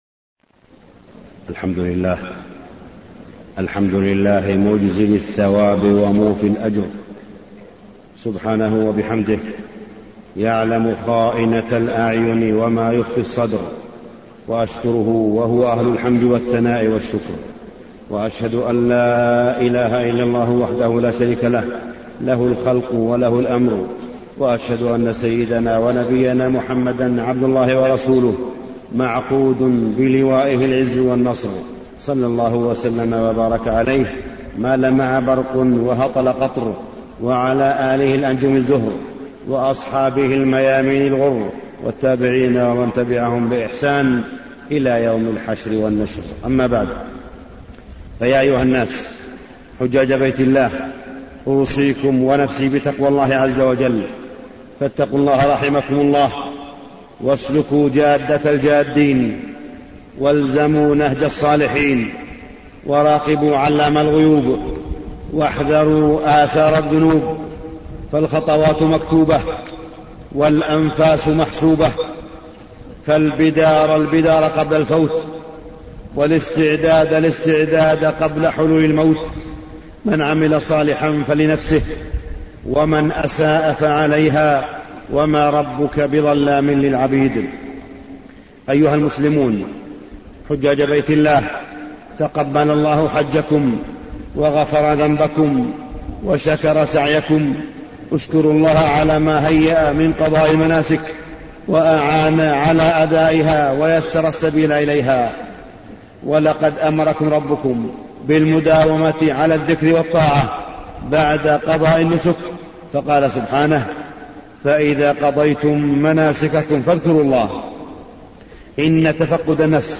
تاريخ النشر ١٨ ذو الحجة ١٤٢٨ هـ المكان: المسجد الحرام الشيخ: معالي الشيخ أ.د. صالح بن عبدالله بن حميد معالي الشيخ أ.د. صالح بن عبدالله بن حميد ماذا بعد الحج